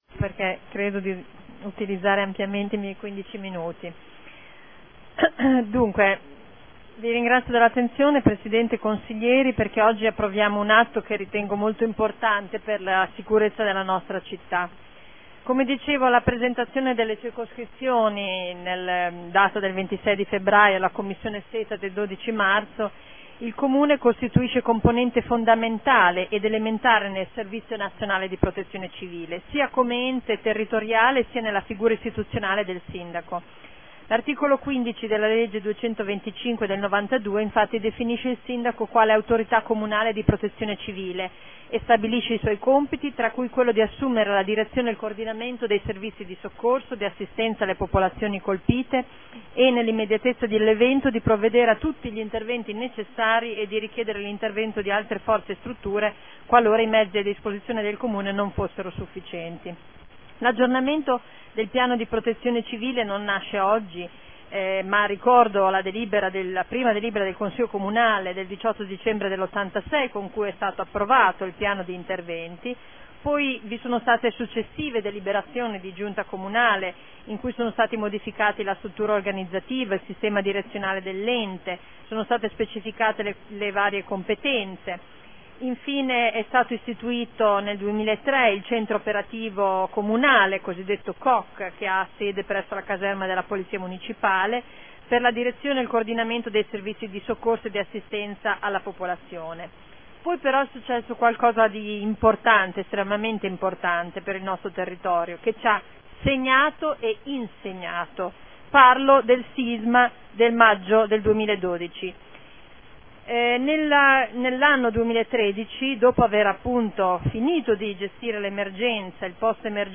Seduta del 20/03/2014 Aggiornamento del Piano comunale di protezione civile